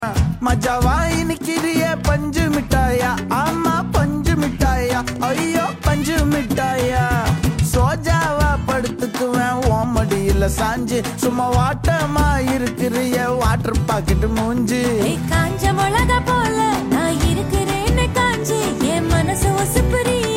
With its catchy melody